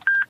beep.ogg